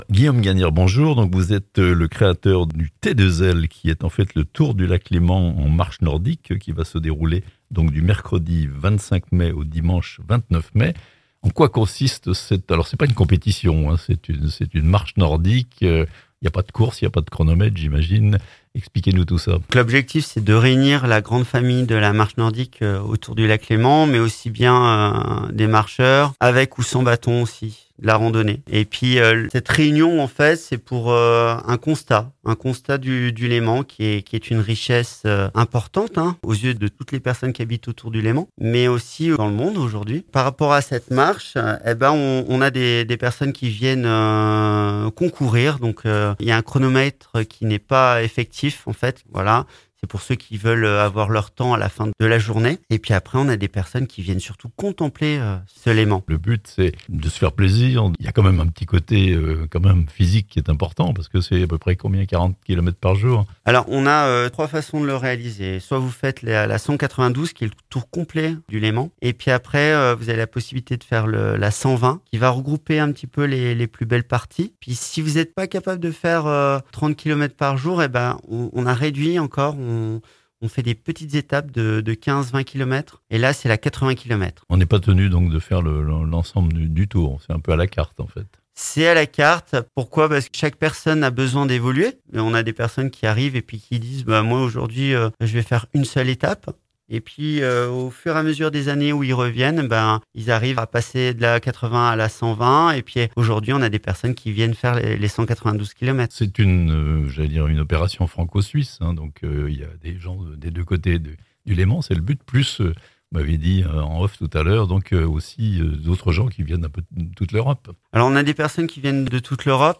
Une idée de rando : le Tour du Léman en marche nordique (interview)